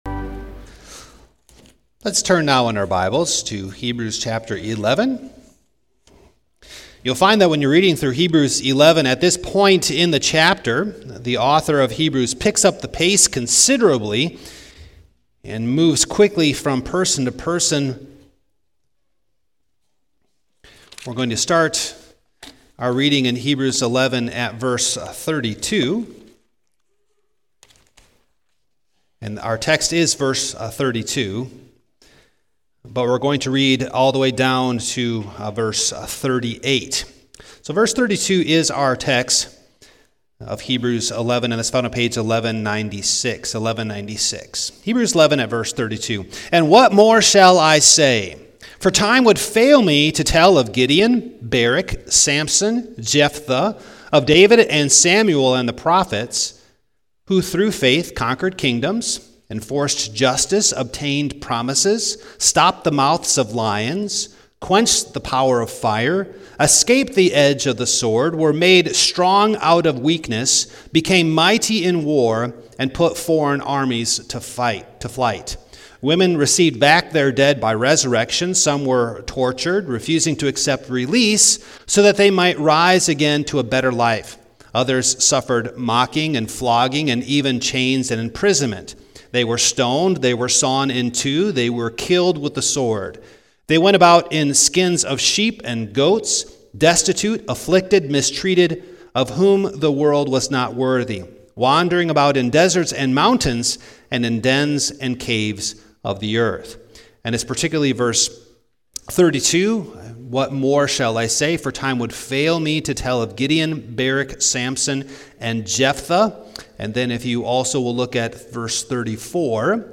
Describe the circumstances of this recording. Passage: Heb. 11:32-38 Service Type: Morning